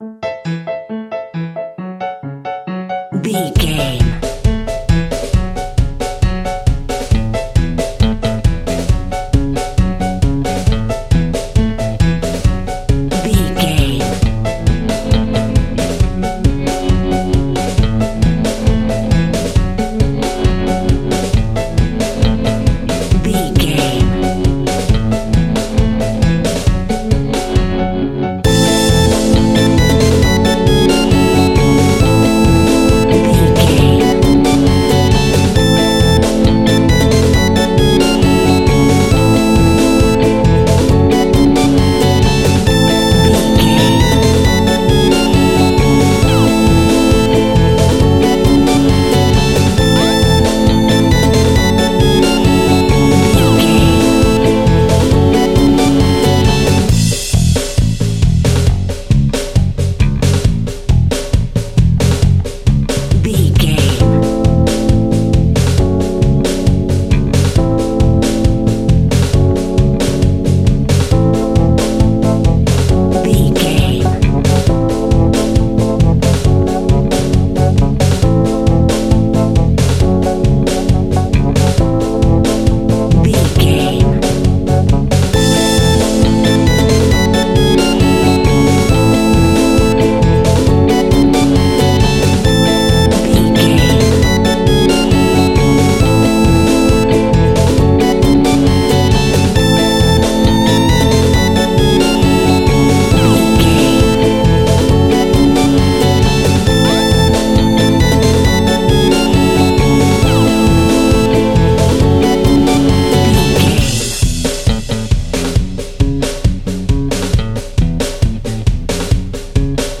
Aeolian/Minor
scary
ominous
eerie
piano
drums
bass guitar
synthesizer
mysterious
horror music
Horror Pads